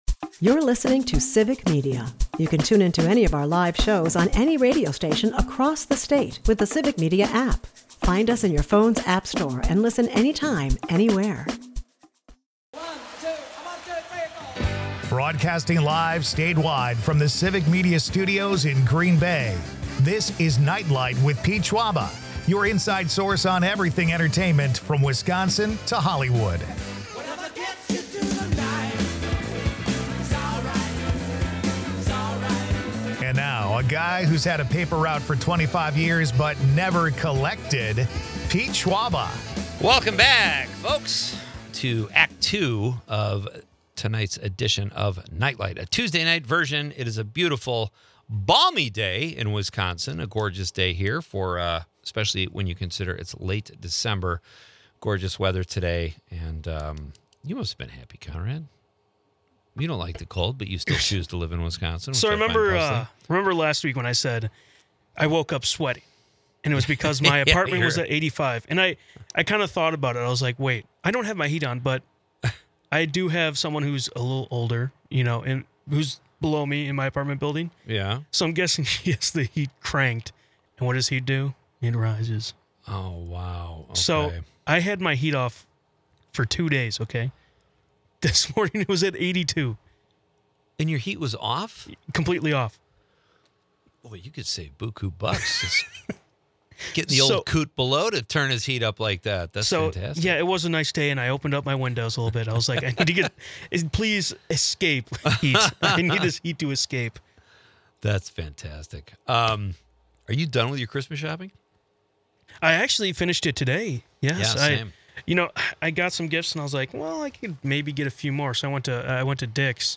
is a part of the Civic Media radio network and airs Monday through Friday from 5-8 pm across Wisconsin.